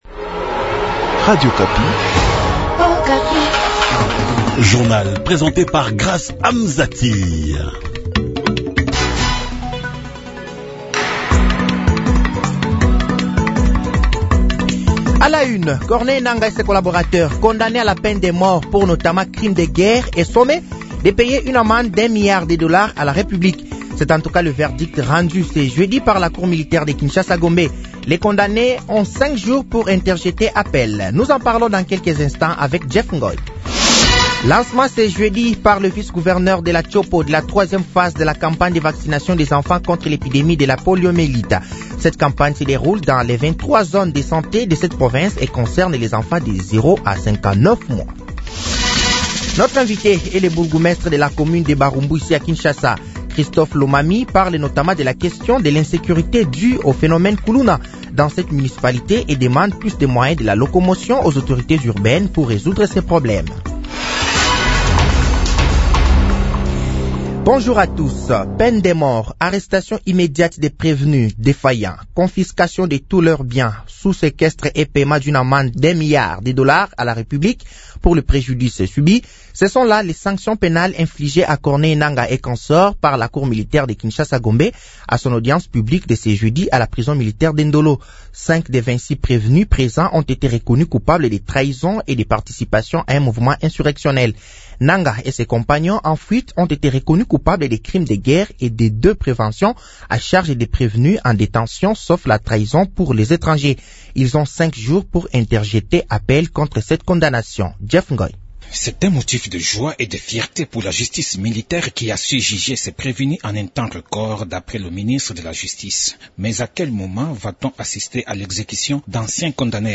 Journal français de 6h de ce jeudi 09 août 2024